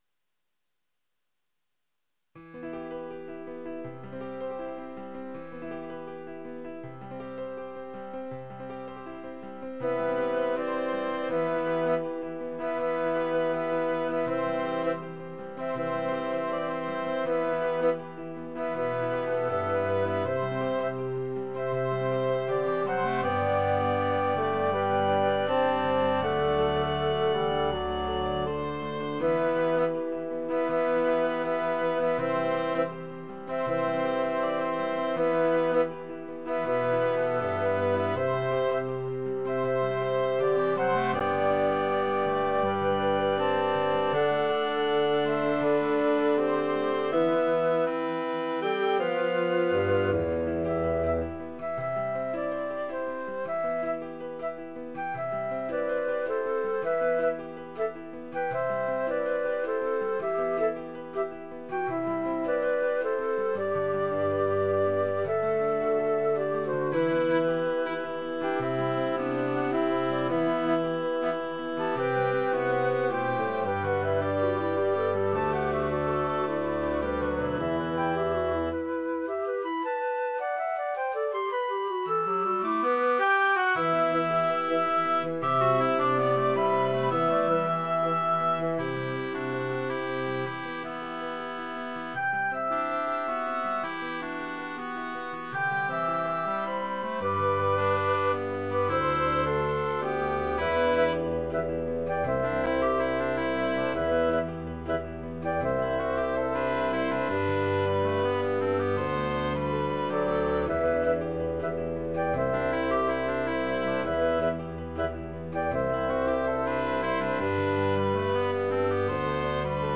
Tags: Piano, Clarinet, Woodwinds